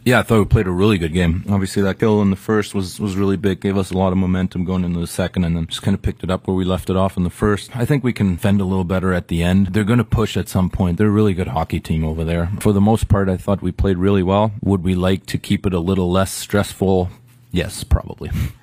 Leon Draisaitl, who has 24 points in 12 games this playoffs spoke to media following the victory and discussed their resilience, even if it got dicey at the end.